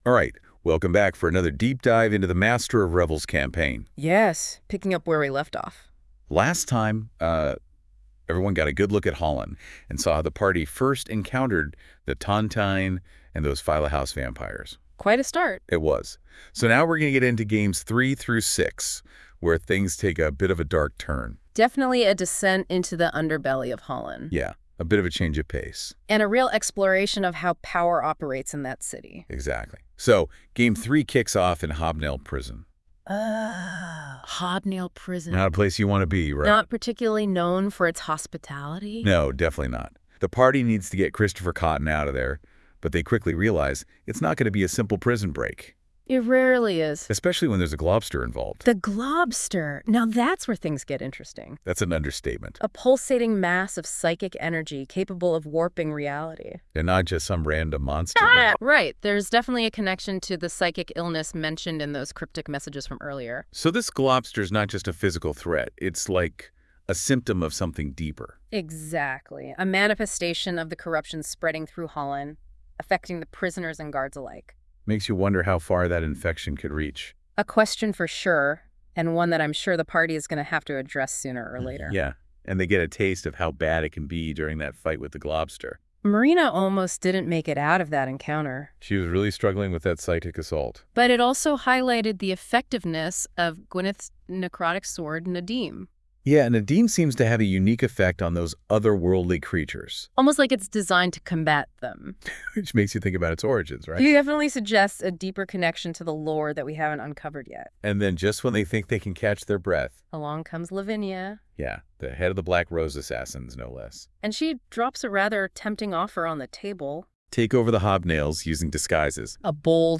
:) Here is a AI-generated podcast describing games 3-6, which follows the earlier AI podcast covering games 1 and 2 .